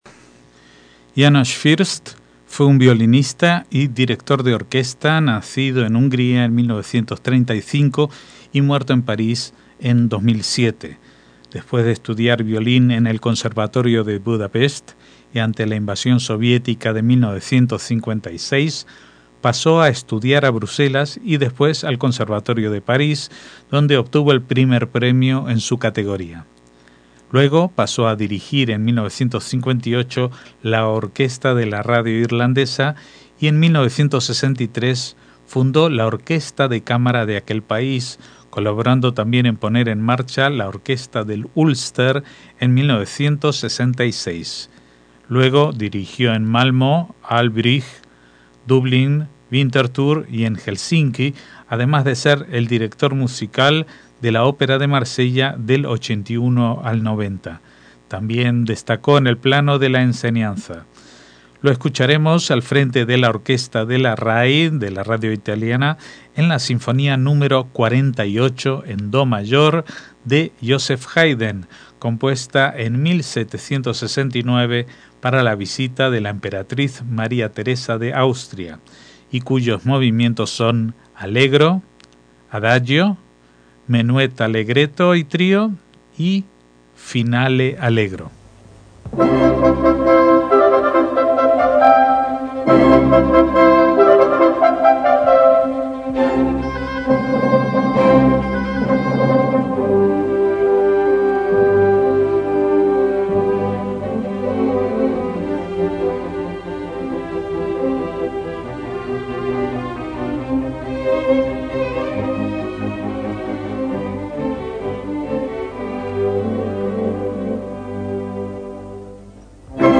MÚSICA CLÁSICA - János Fürst fue un violinista y director de orquesta nacido en Hungría en 1935 y muerto en 2007.
Lo escucharemos al frente de la orquesta de la RAI, de la Radio Italiana, en la Sinfonía Nº 48 en do mayor, de Haydn, com